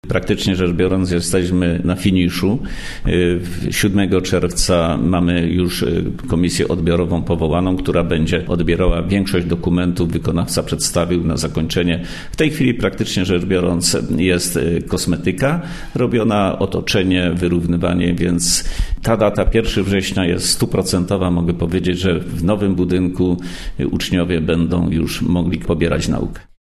– mówił wicestarosta powiatu wieruszowskiego, Stefan Pietras.